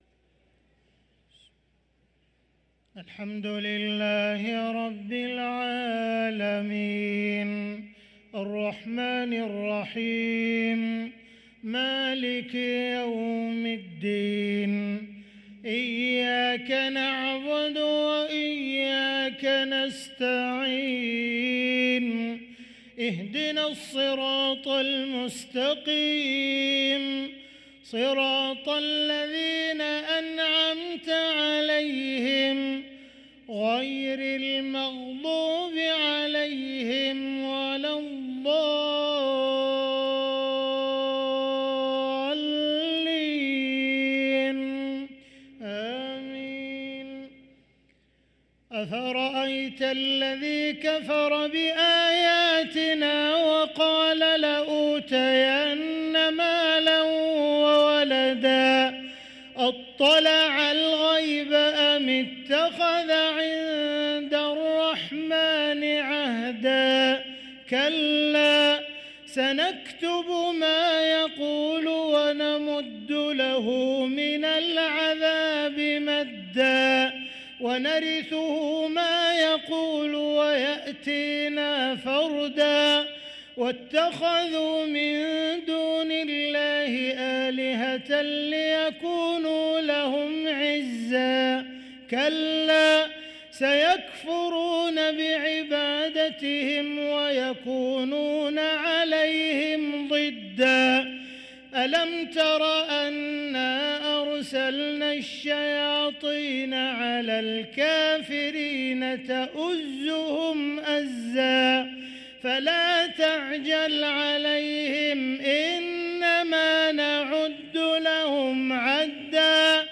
صلاة العشاء للقارئ عبدالرحمن السديس 9 ربيع الآخر 1445 هـ